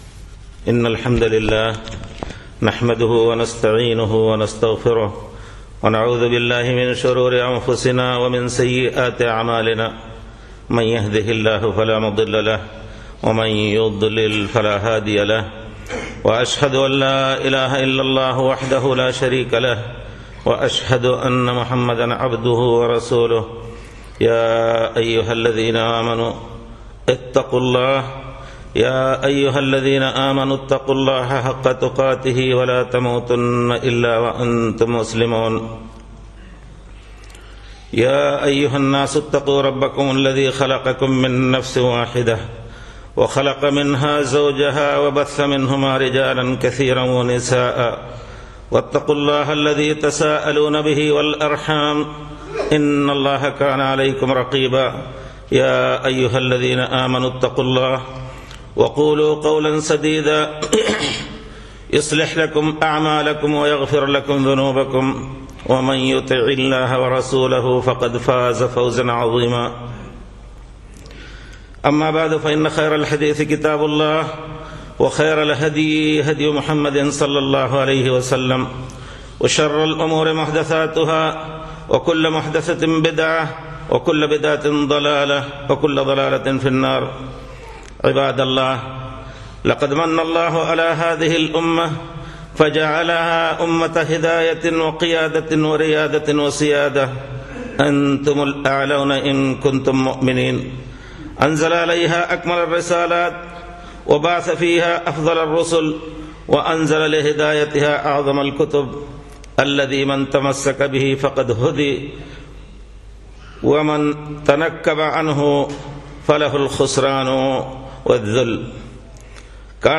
نصيحة هامة لعامة الأمة - خطبة